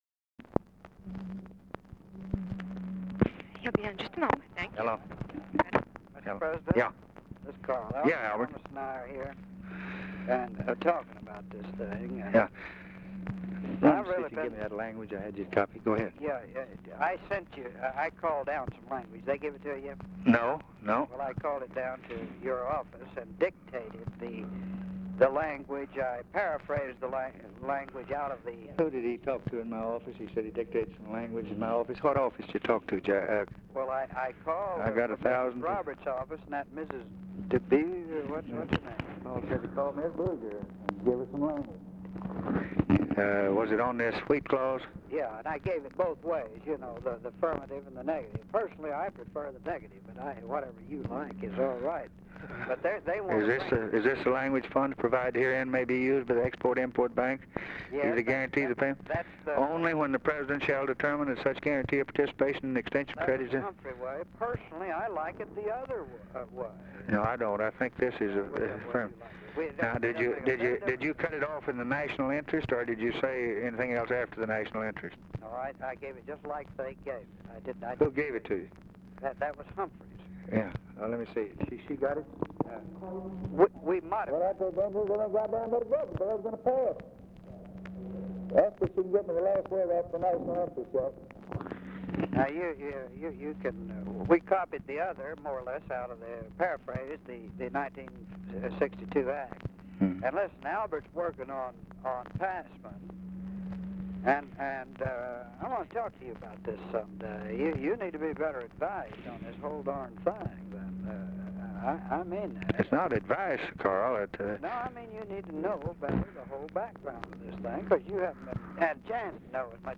Conversation with CARL ALBERT, December 21, 1963
Secret White House Tapes